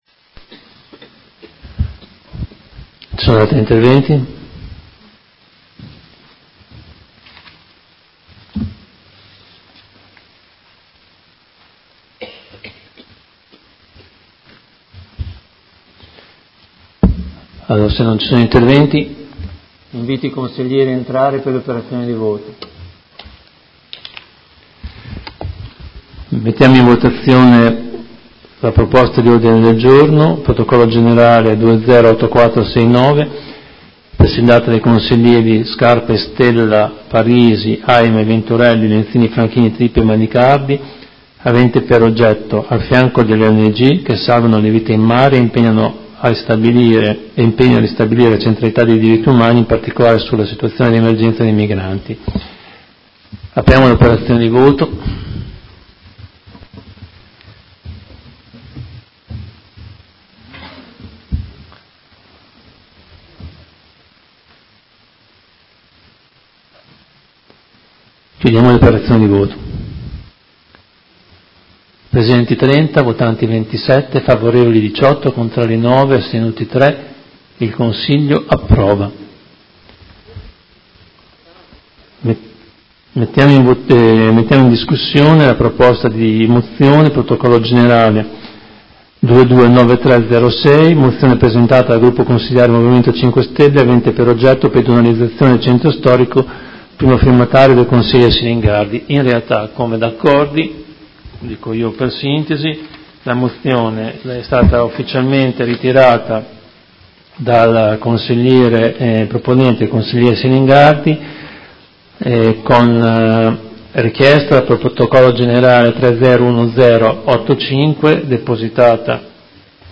Fabio Poggi — Sito Audio Consiglio Comunale
Seduta del 10/10/2019 Mette ai voti Ordine del Giorno Prot. Gen. n. 208469 presentato dai Consiglieri Scarpa e Stella (Sinistra per Modena), Consigliera Parisi (Modena Solidale), Consigliera Aime (Verdi) e Consiglieri Venturelli, Lenzini, Franchini, Tripi e Manicardi (PD) avente per oggetto: Al fianco delle ONG che salvano le vite in mare e impegno a ristabilire la centralità dei Diritti Umani, in particolare nelle situazioni di emergenza dei migranti: approvato.